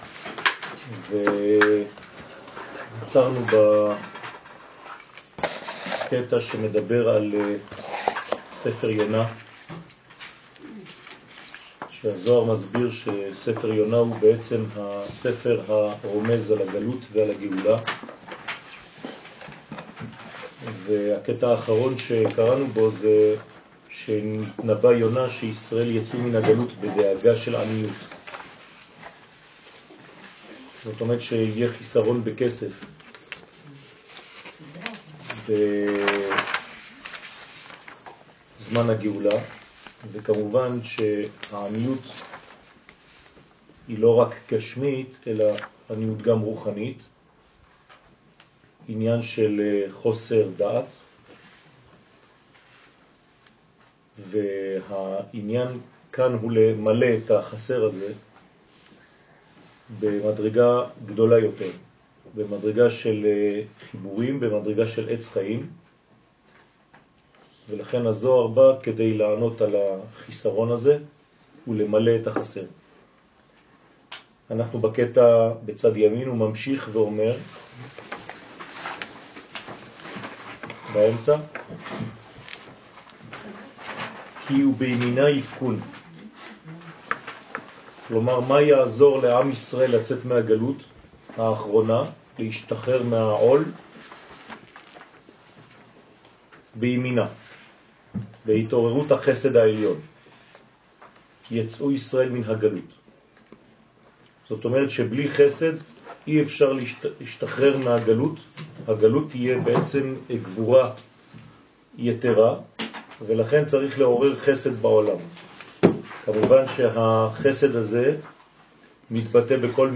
תיקון זוהר כ חסידות שיעור מ 11 מאי 2016 02H 13MIN הורדה בקובץ אודיו MP3 (122.42 Mo) הורדה בקובץ אודיו M4A (21.37 Mo) הורדה בקובץ וידאו MP4 (344.12 Mo) TAGS : תיקון זוהר זוהר עברית שיעורים שיעורים קצרים